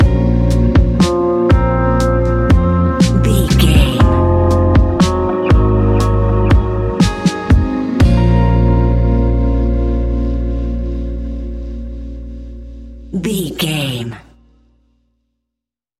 Ionian/Major
A♯
laid back
Lounge
sparse
new age
chilled electronica
ambient
atmospheric